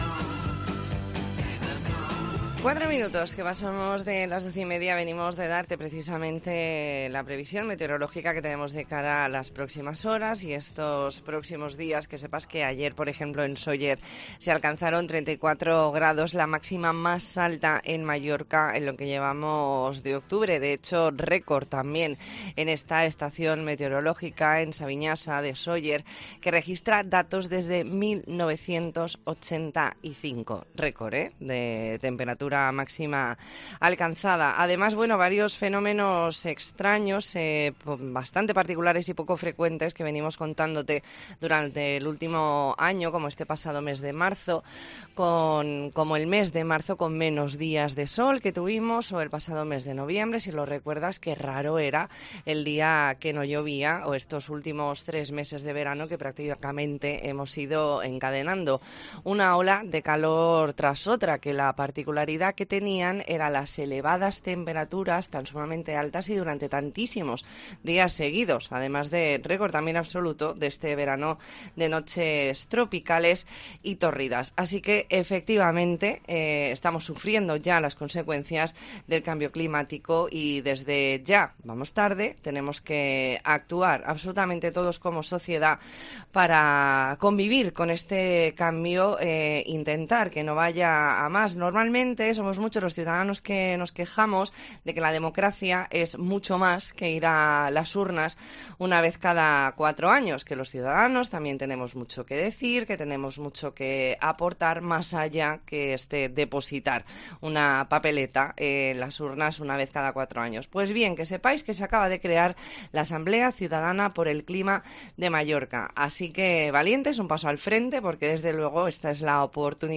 Entrevista en La Mañana en COPE Más Mallorca, jueves 27 de octubre de 2022.